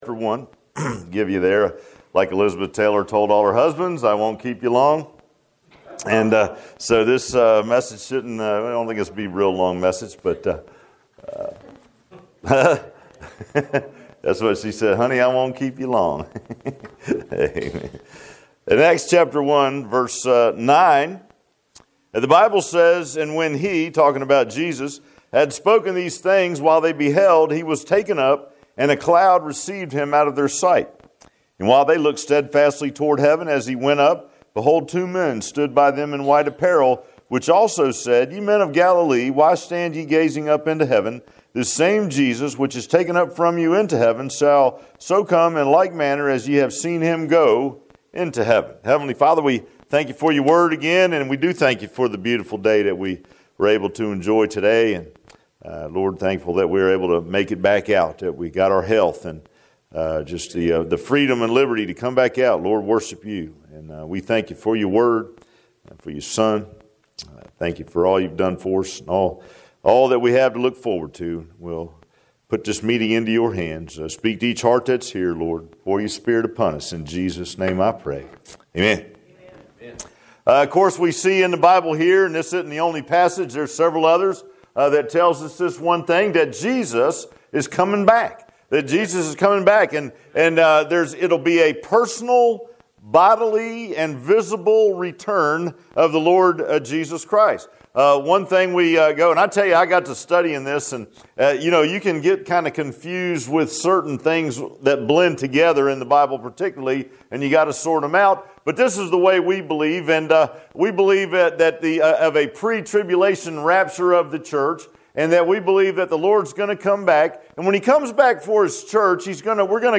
Acts 1:9-11 Service Type: Sunday PM Bible Text